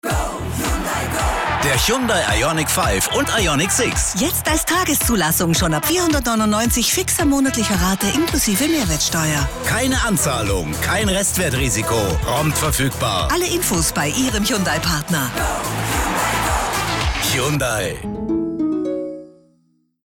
Hyundai Radio Spot